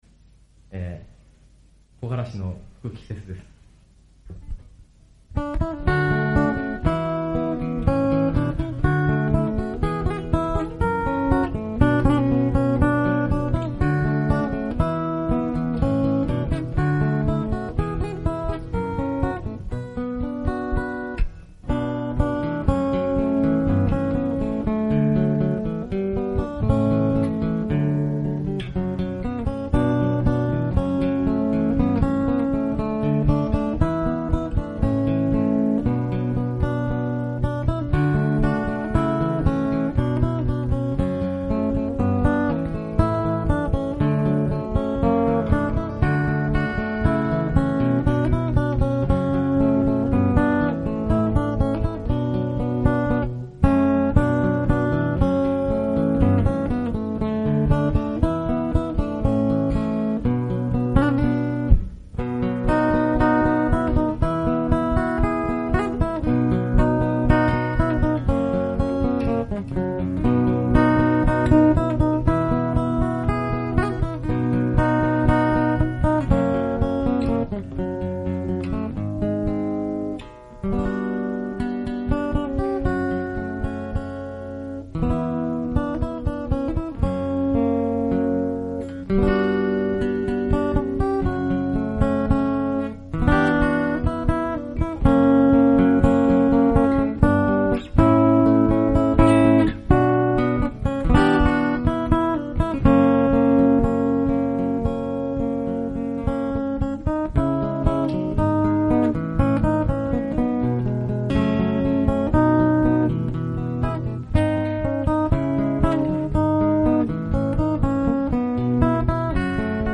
A.guitar
ライブ音源で、アコギのインストです。